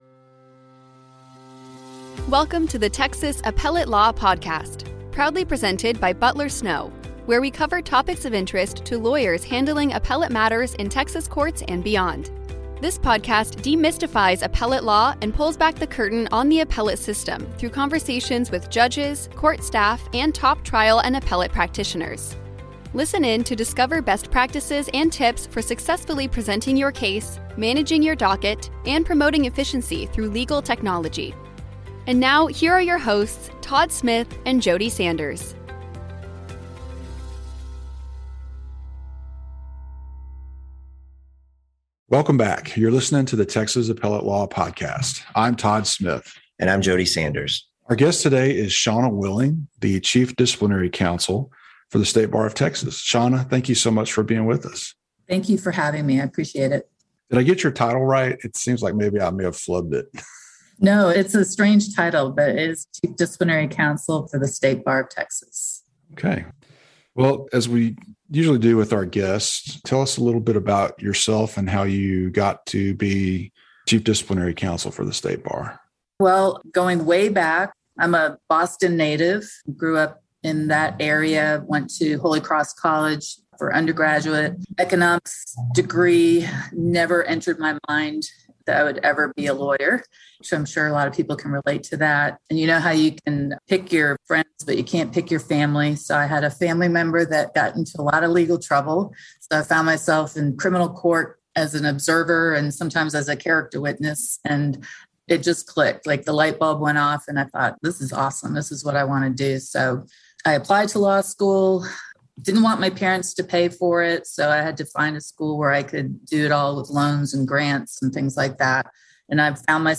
Join us for a conversation important to all Texas lawyers.